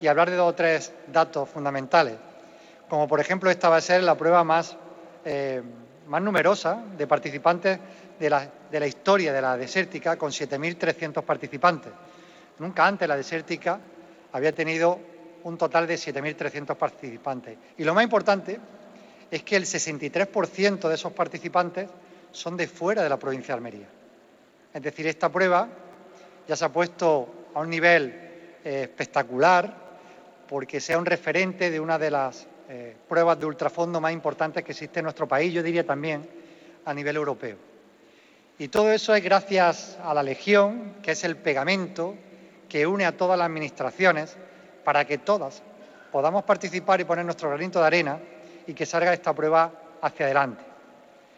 El Pabellón Moisés Ruiz de la Diputación de Almería ha acogido la presentación de la VII edición de La Desértica, una cita deportiva organizada por la Brigada de La Legión.